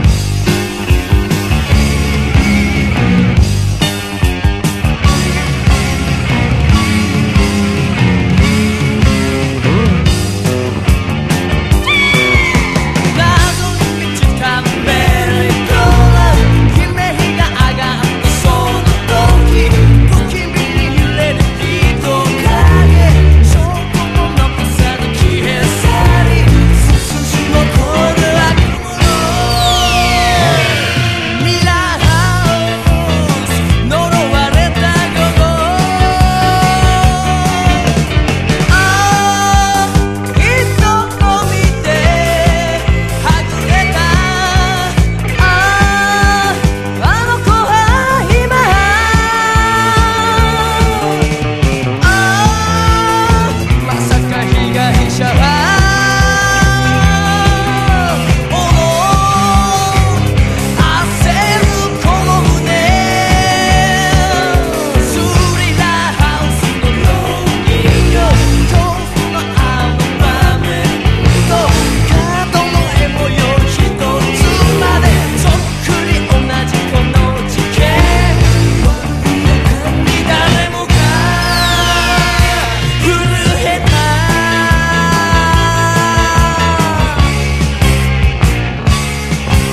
当時のヒット歌謡からアニメ主題歌までを行進曲～マーチにした珍盤！